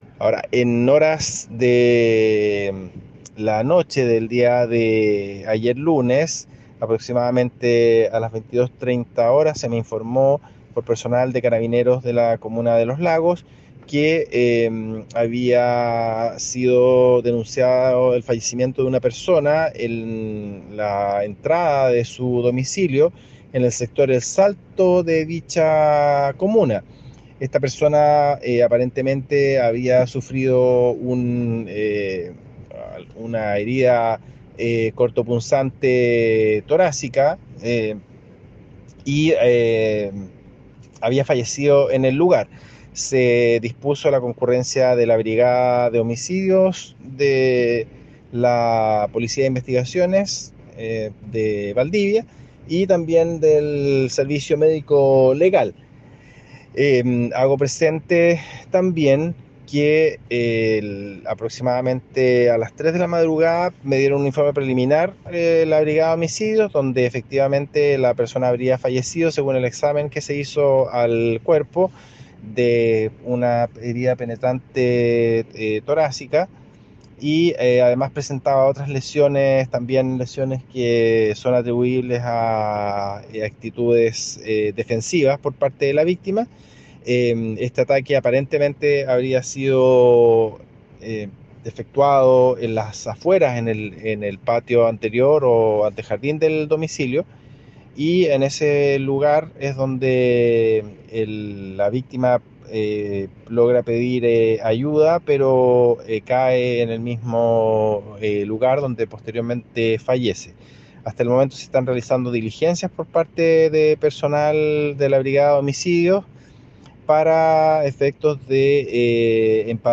Fiscal Carlos Silva entrega nuevos antecedentes del accidente.